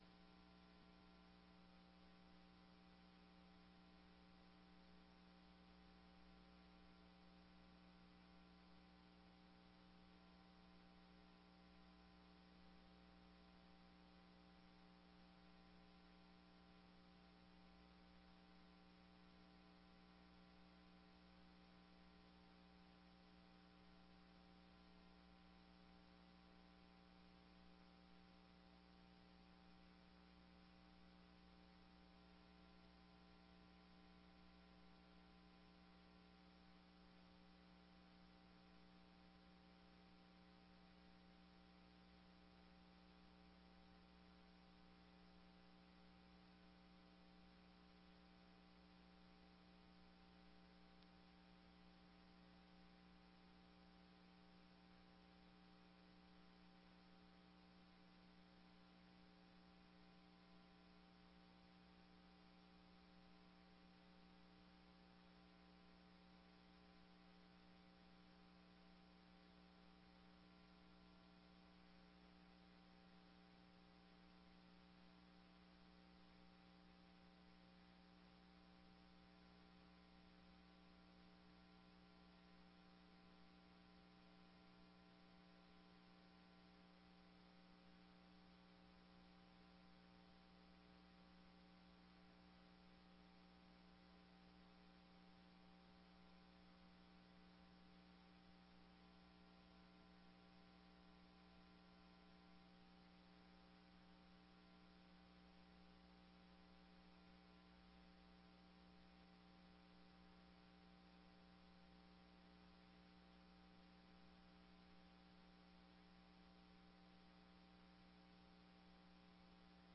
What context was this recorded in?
15ª Sessão Ordinária de 2019